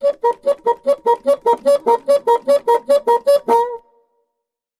Звуки фагота
Фагот – уникальный деревянный духовой инструмент с глубоким тембром, который часто используют в симфонических оркестрах.
Этот звук часто применяют в комедийных сценах